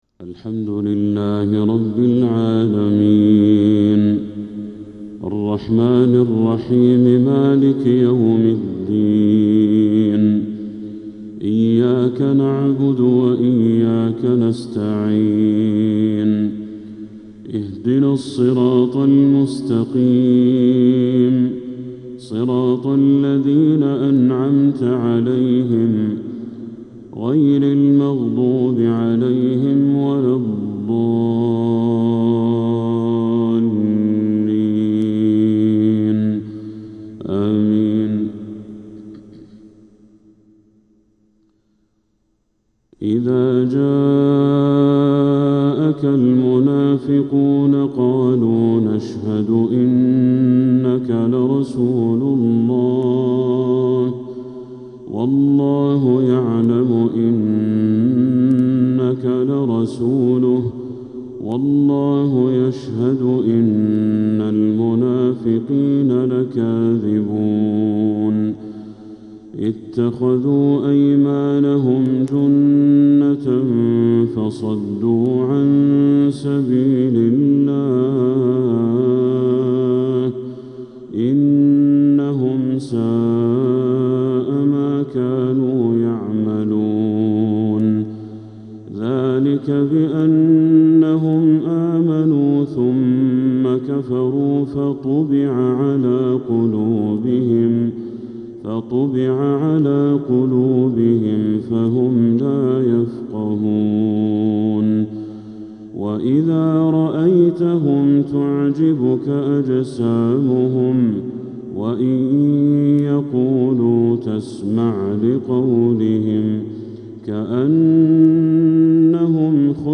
فجر الجمعة 9 محرم 1447هـ | سورتي المنافقون و الليل كاملة | Fajr prayer from Surah al-Munafiqun and al-Layl 4-7-2025 > 1447 🕋 > الفروض - تلاوات الحرمين